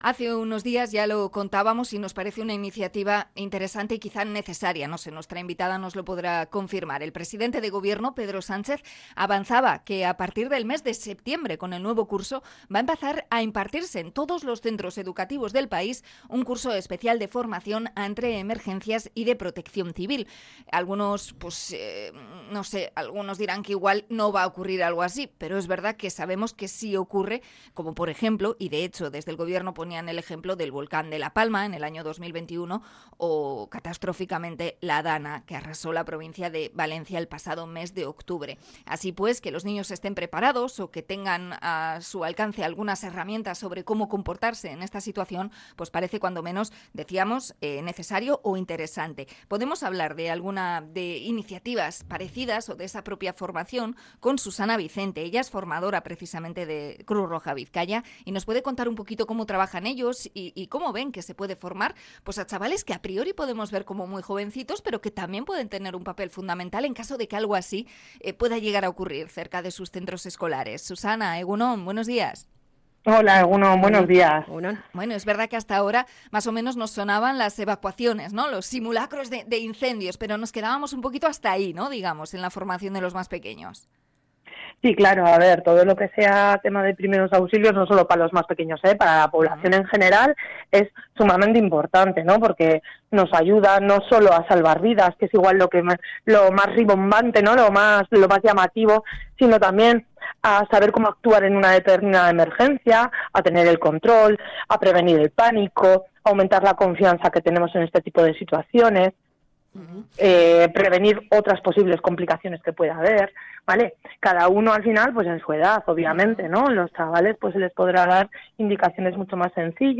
Entrevista a Cruz Roja Bizkaia por la formación ante catástrofes en las escuelas